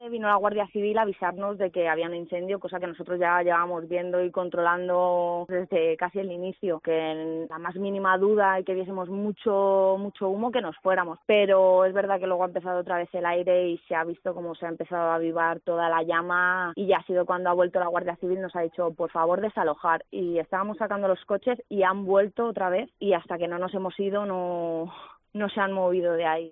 una de las afectadas